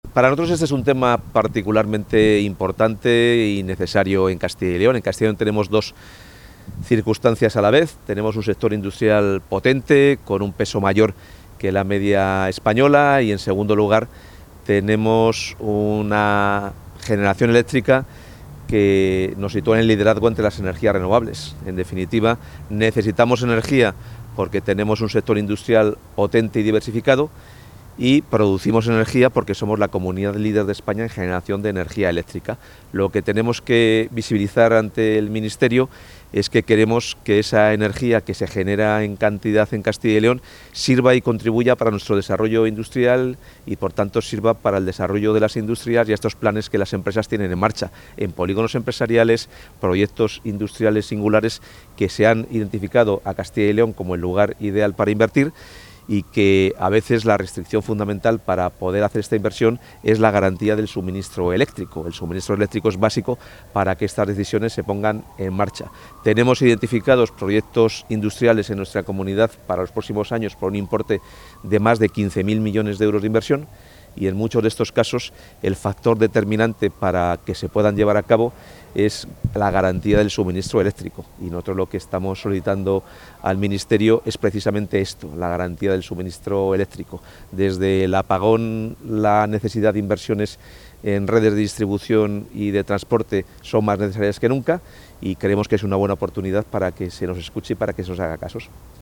Declaraciones del consejero de Economía y Hacienda previas a la reunión con el secretario de Estado de Energía